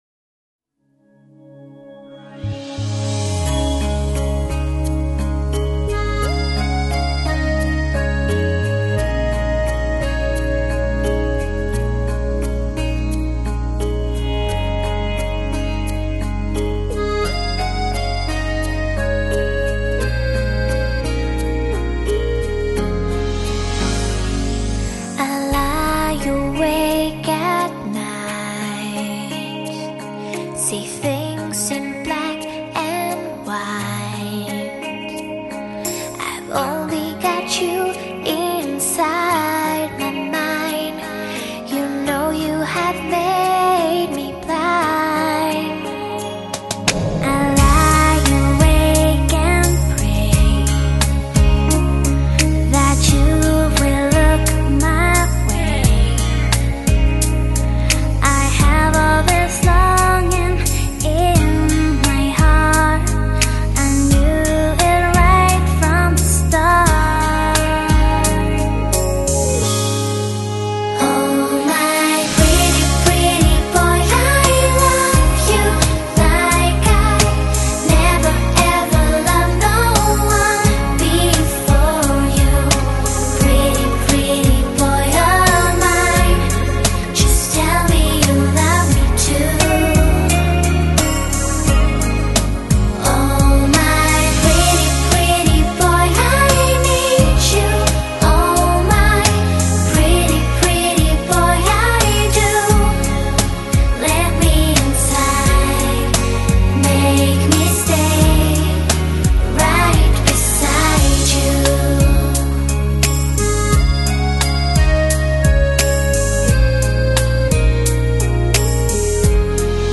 音乐风格: 流行
主唱、钢琴、空心吉他、鼓、大键琴
演唱、节奏吉他、空心吉他